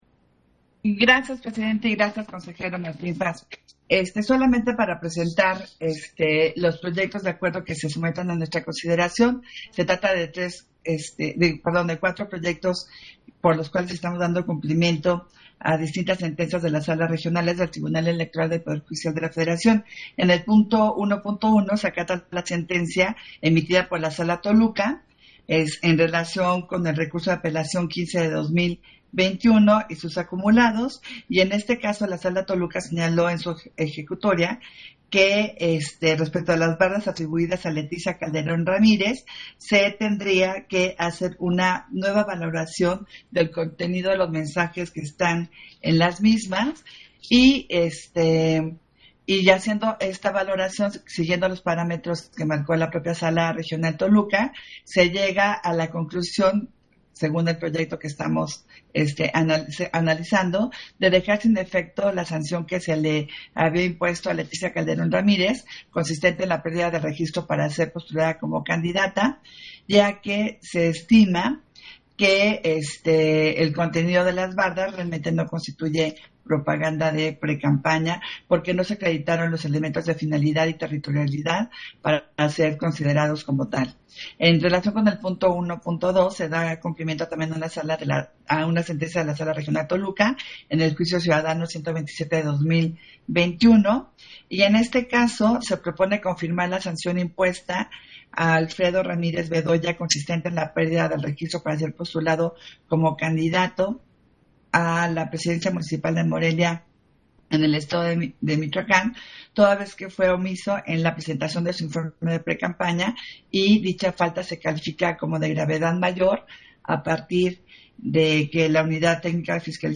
Intervención de Adriana Favela, en Sesión Extraordinaria, en que se da cumplimiento a sentencias dictadas por la Sala Regional Toluca del TEPJF en materia de fiscalización